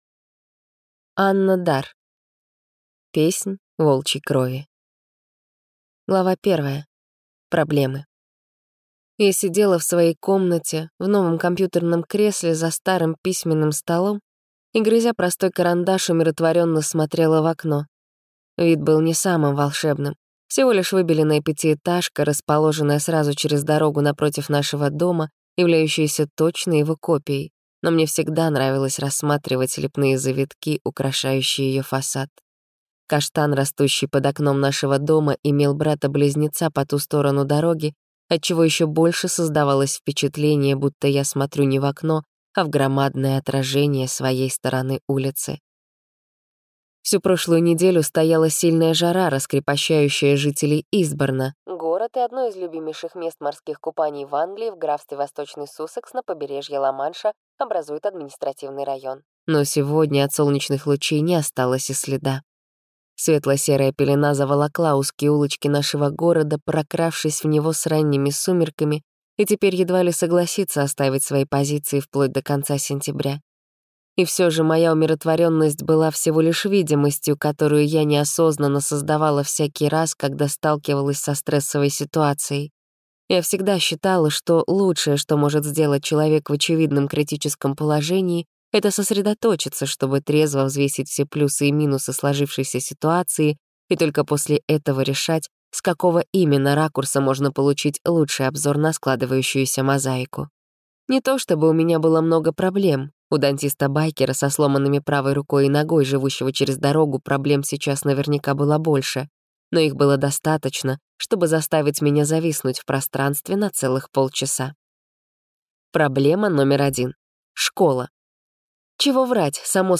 Контрольная схватка (слушать аудиокнигу бесплатно) - автор Александр Тамоников